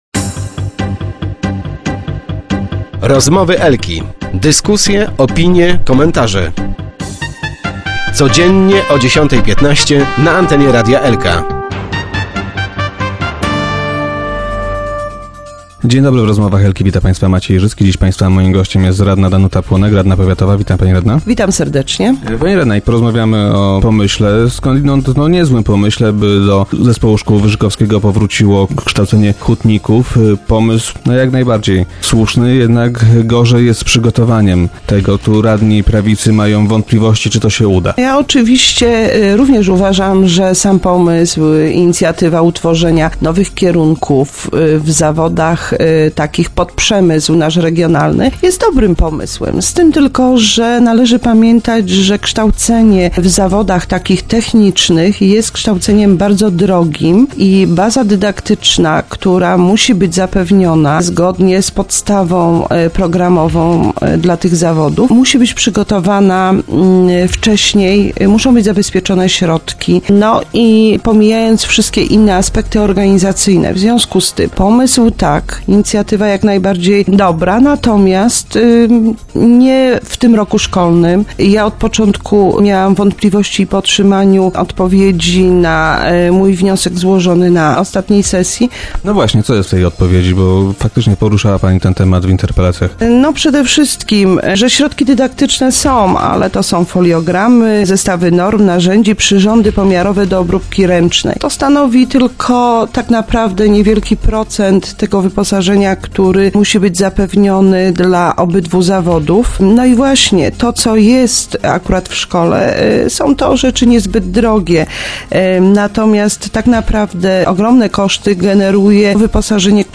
Powiatowi radni opozycji mają jednak wątpliwości, czy uruchomienie nowych kierunków jest odpowiednio przygotowane. Gościem Rozmów Elki była dziś Danuta Ponek, radna powiatowa PiS.